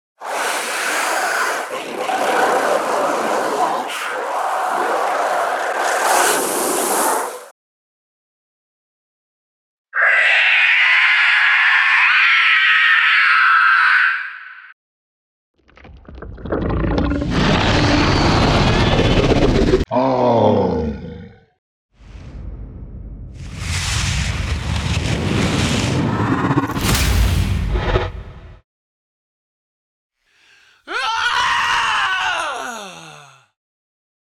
In this release you will find the sounds necessary to design your creatures, from roars to movement and vocalisations.
All samples were recorded at 96kHz 24 bit with a Zoom H5 and Sennheiser ME 67.